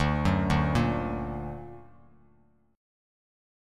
D+ Chord
Listen to D+ strummed